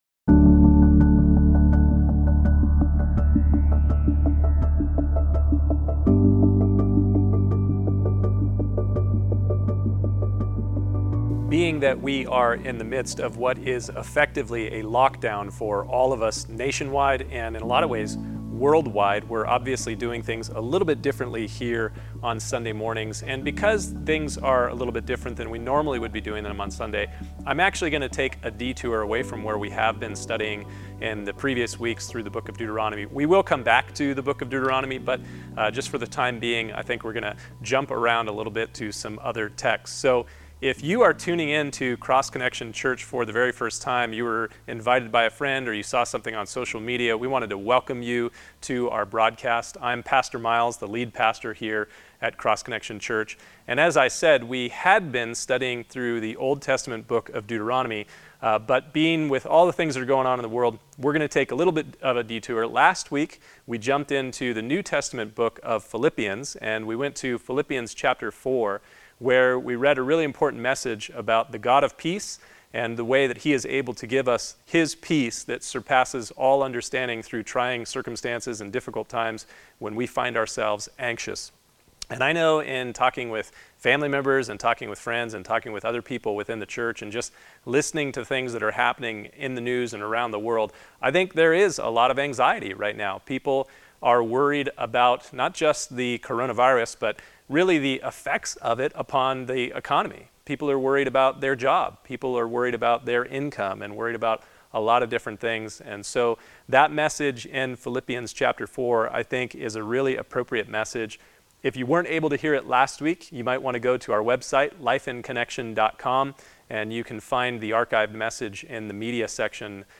MP3 Download | Download Sermon Guide PDF